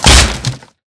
woodbreak.ogg